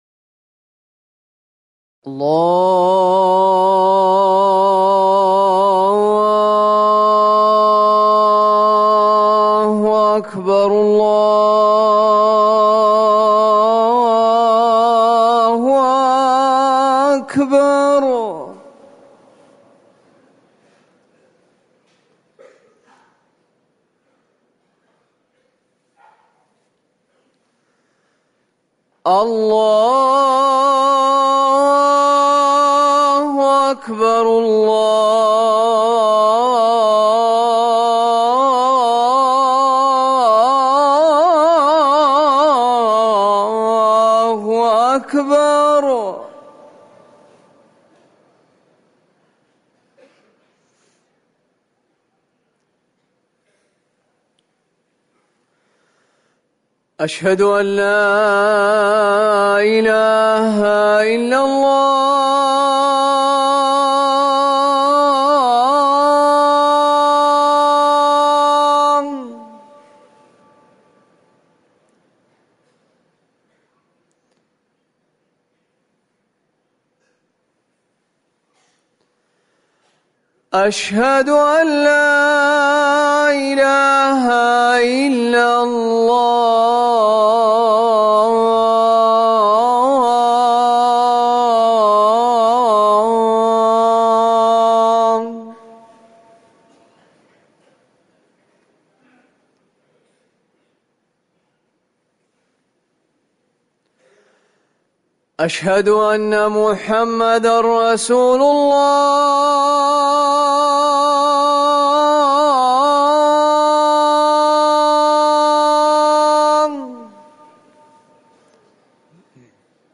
أذان الفجر الأول - الموقع الرسمي لرئاسة الشؤون الدينية بالمسجد النبوي والمسجد الحرام
تاريخ النشر ٢٩ محرم ١٤٤١ هـ المكان: المسجد النبوي الشيخ